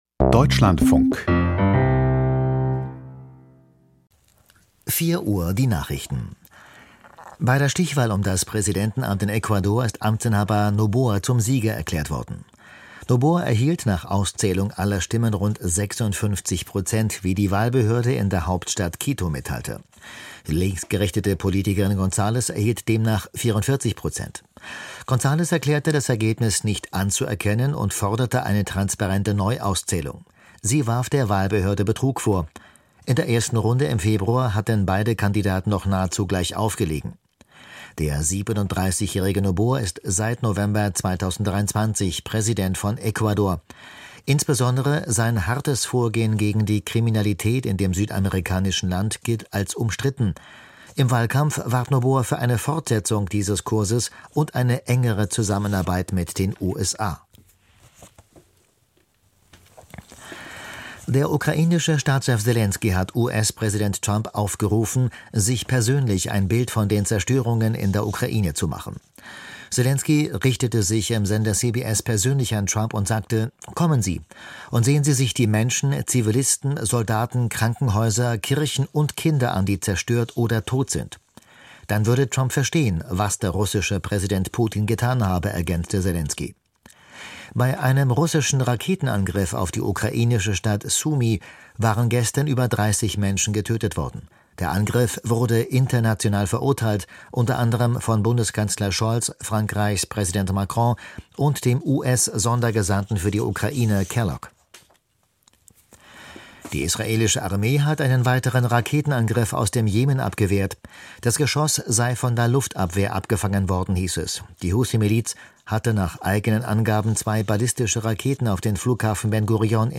Die Deutschlandfunk-Nachrichten vom 14.04.2025, 04:00 Uhr